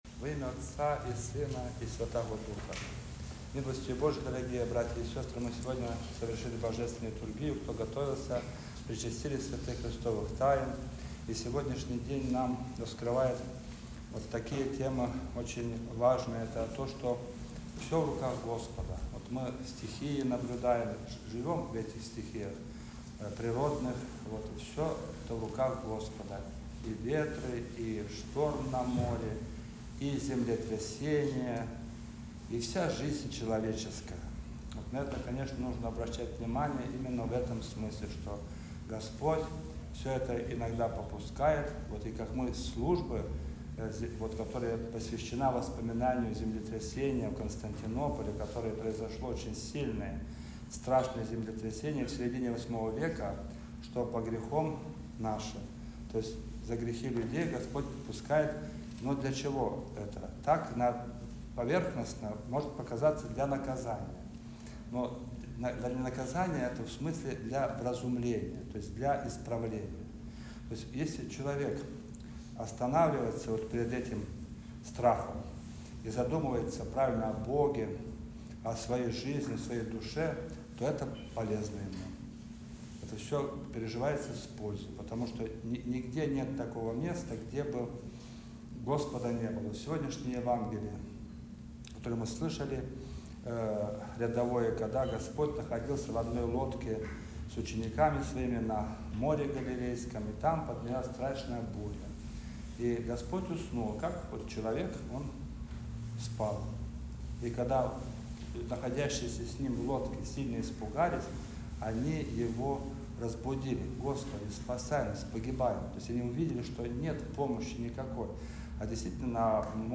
Проповедь